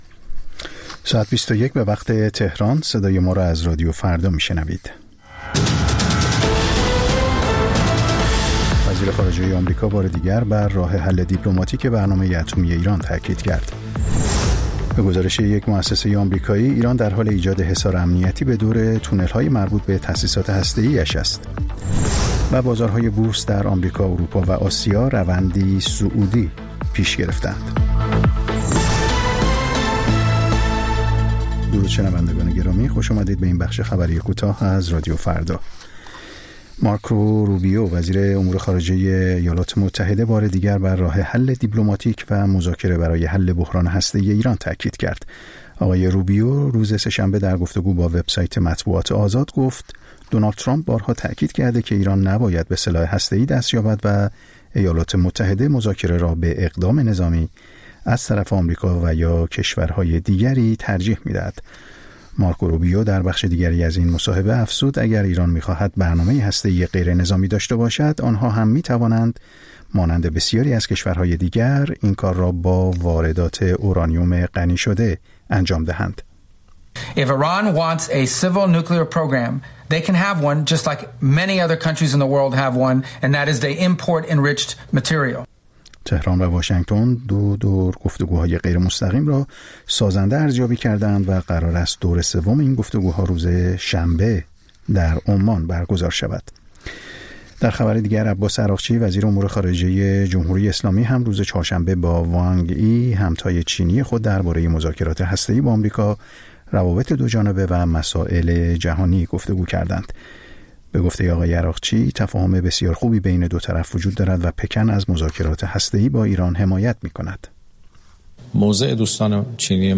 سرخط خبرها ۲۱:۰۰